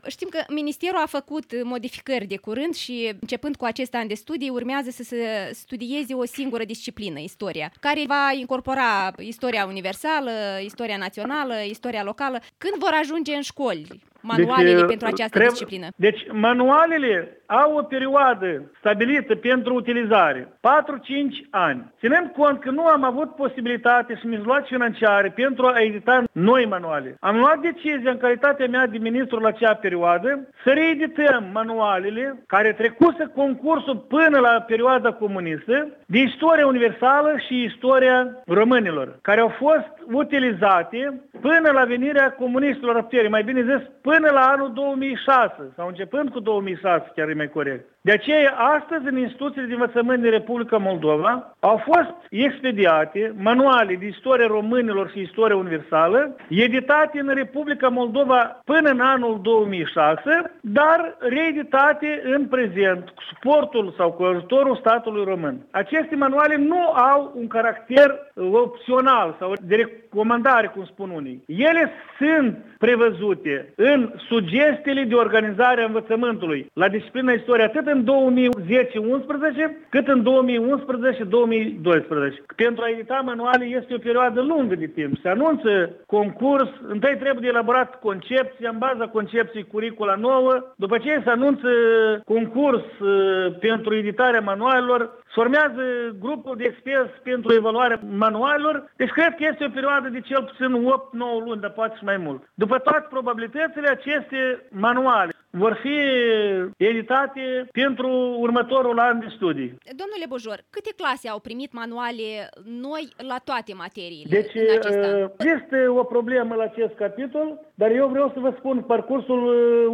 Un interviu cu Leonid Bujor, consilierul pentru educaţie al primului-ministru.